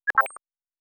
pgs/Assets/Audio/Sci-Fi Sounds/Interface/Error 01.wav at master
Error 01.wav